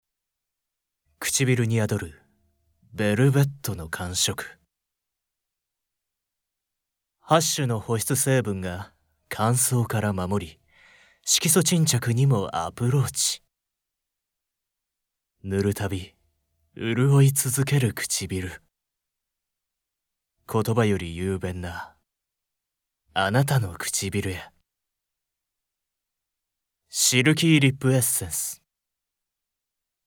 ▶男性
ボイスサンプル